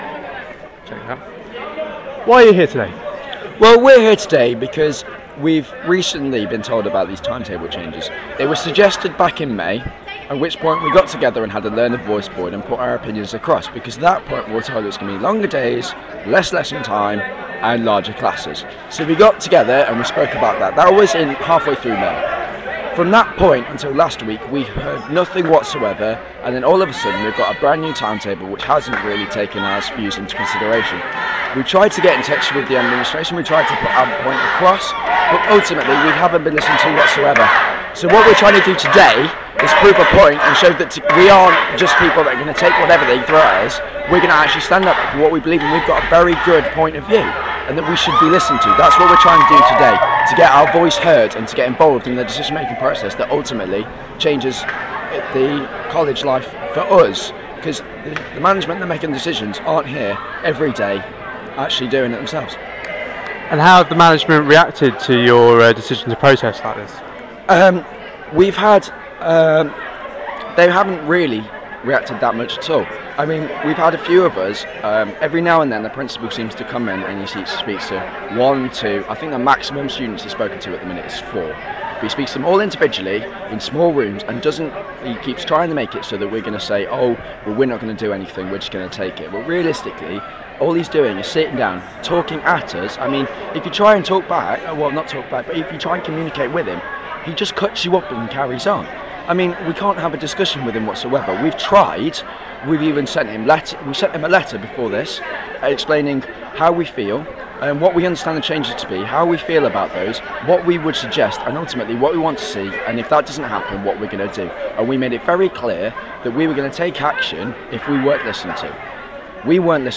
Here's the full audio of an interview with a striking NCN student outside the High Pavement campus this morning.